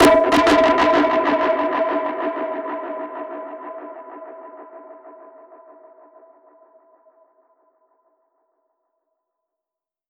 DPFX_PercHit_C_95-11.wav